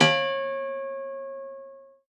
53w-pno05-C3.wav